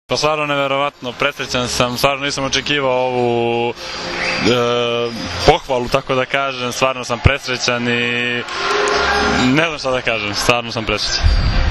IZJAVA SREĆKA LISINCA